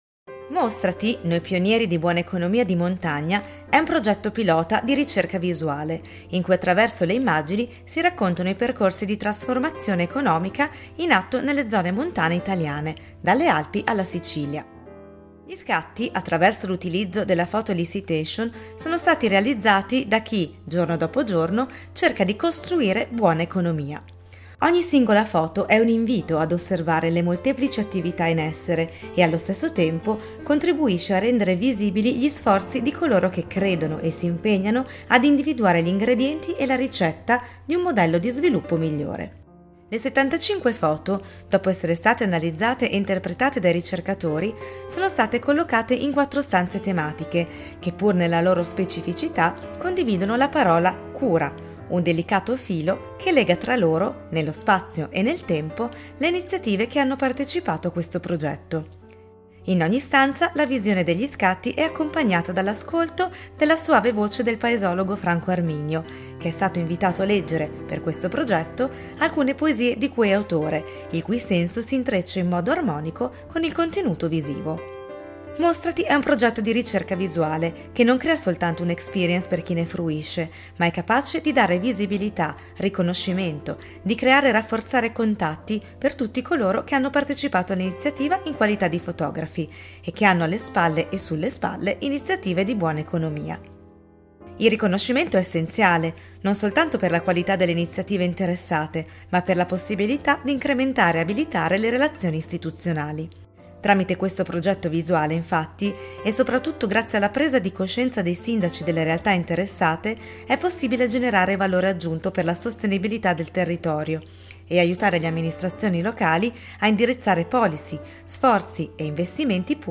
L'introduzione alla mostra e alle diverse "stanze" sarà fatta dal noto paesologo Franco Arminio attraverso la lettura di sue poesie che aiutano il visitatore a cogliere il senso del progetto.